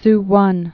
(swŭn)